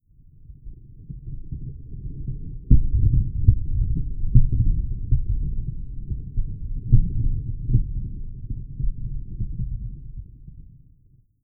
THUNDER_Rumble_04_mono.wav